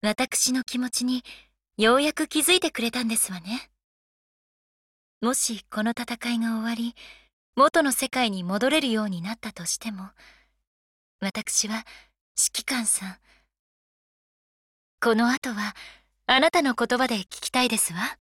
• 声优：佐藤利奈